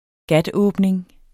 Udtale [ ˈgadˌɔːbneŋ ]